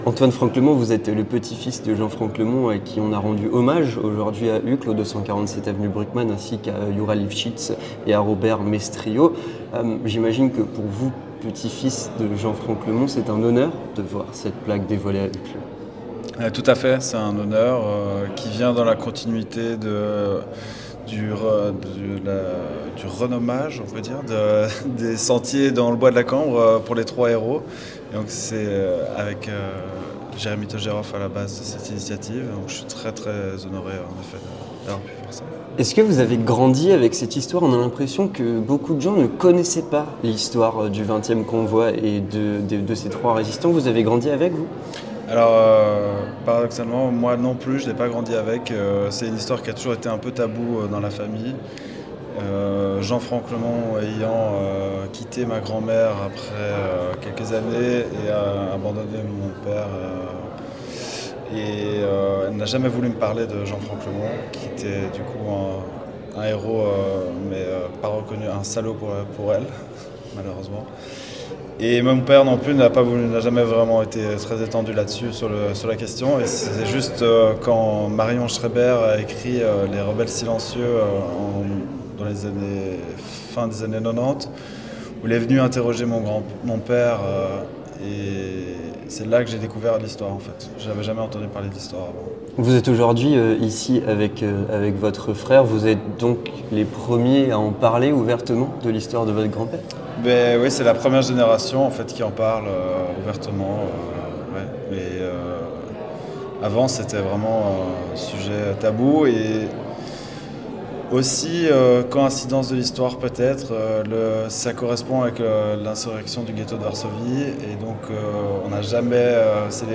Entretien du 18h - Une plaque commémorative en l'honneur de Youra Livschitz, Jean Franklemont et Robert Maistrieau